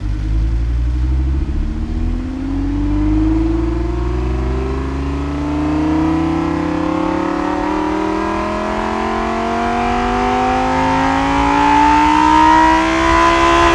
rr3-assets/files/.depot/audio/Vehicles/v10_03/v10_03_accel.wav
v10_03_accel.wav